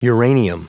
Prononciation du mot uranium en anglais (fichier audio)
Prononciation du mot : uranium